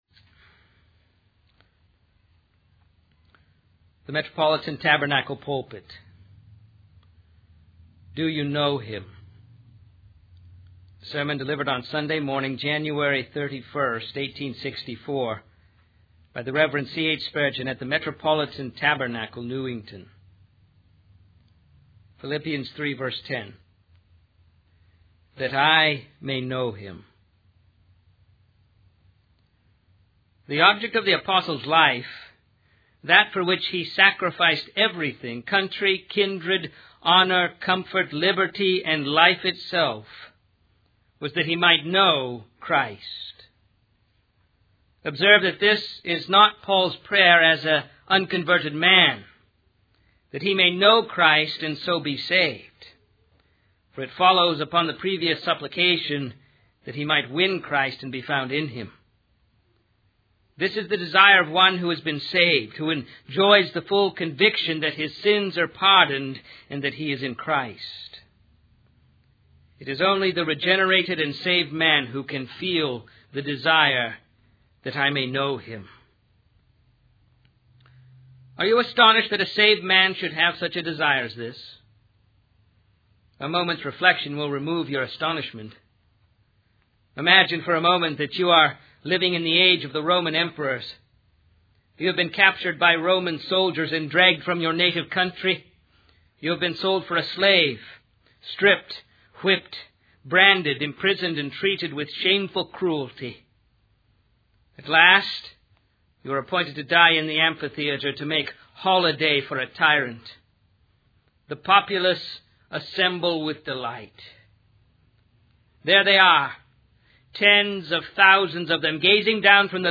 In this sermon, the speaker paints a vivid picture of a person who has been captured and enslaved by Roman soldiers during the time of the Roman emperors.